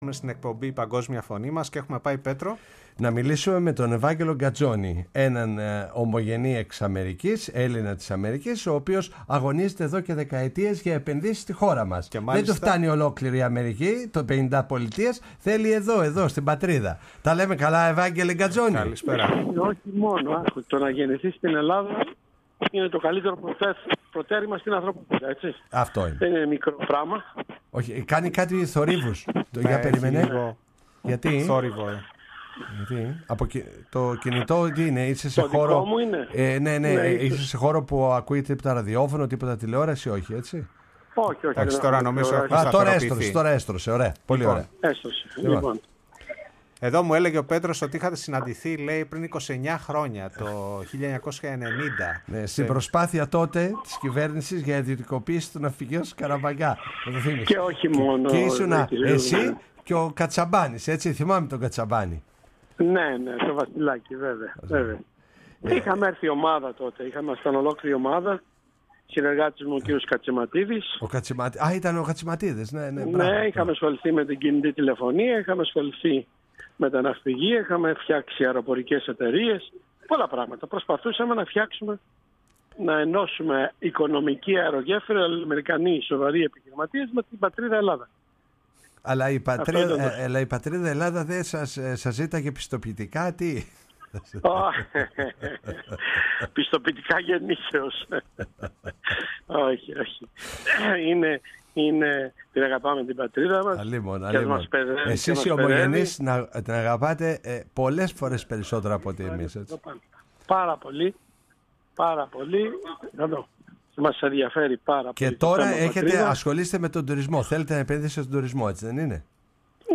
Στην ομογένεια των Ηνωμένων Πολιτειών ήταν αφιερωμένη η εκπομπή «Η Παγκόσμια Φωνή μας» στο ραδιόφωνο Φωνή της Ελλάδας.